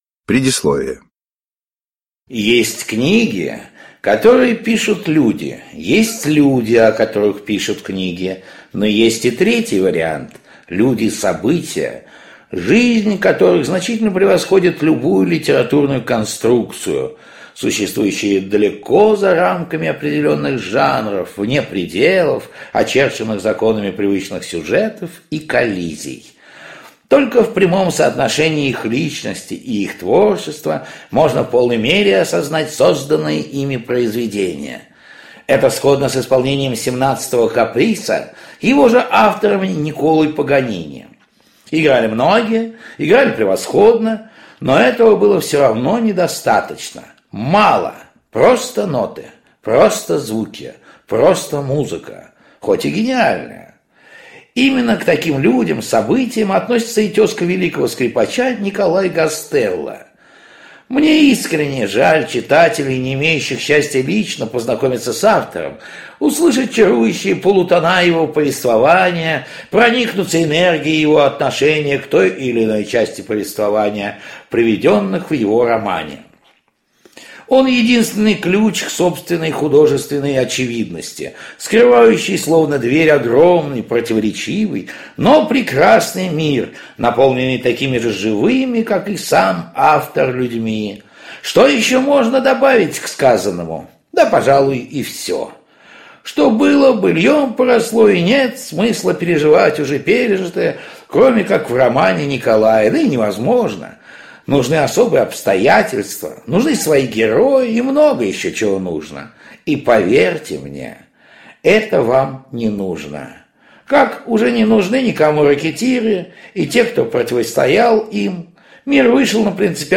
Аудиокнига Рэкетиры никому не нужны | Библиотека аудиокниг
Aудиокнига Рэкетиры никому не нужны Автор Николай Гастелло Читает аудиокнигу Евгений Дятлов.